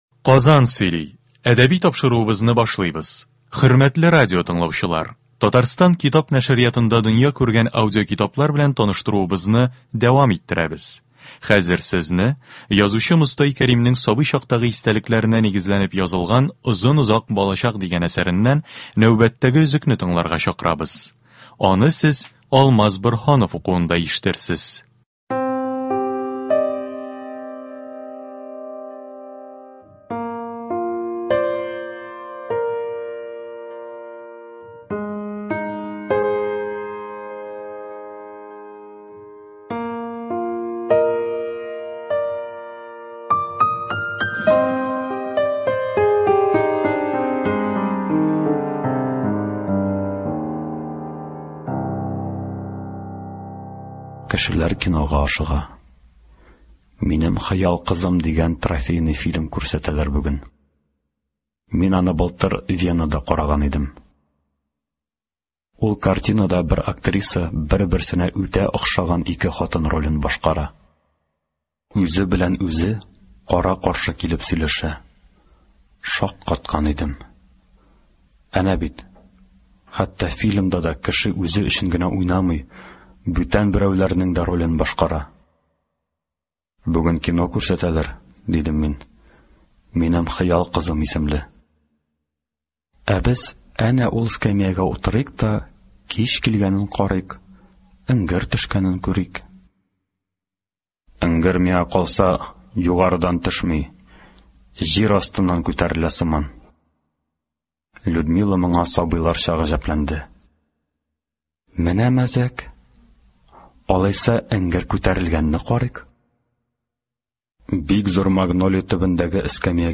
Китап тыңлыйбыз. 13 апрель.